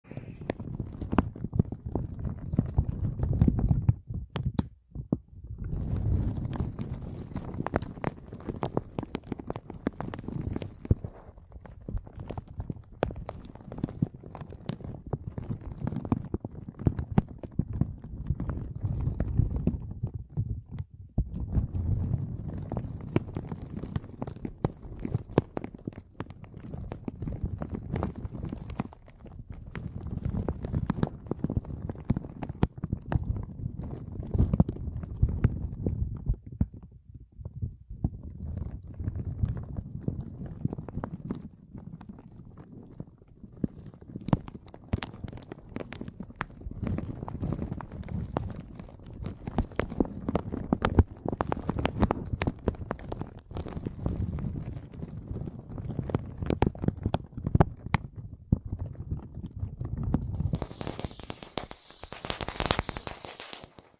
Звуки АСМР
звук скрипа мягкого пластика у микрофона